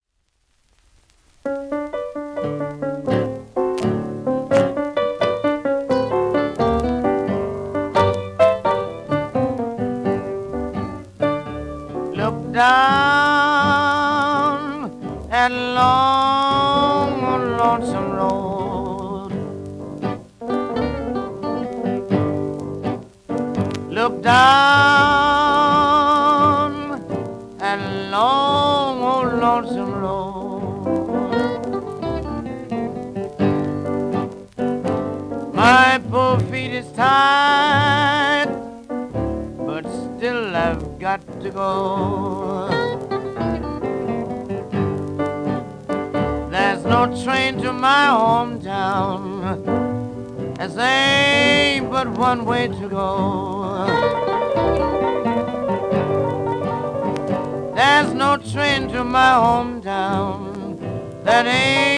盤質A-/B+ *小キズ,ややフォルテ荒
1942年録音